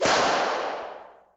Play, download and share big whap original sound button!!!!
big-whap.mp3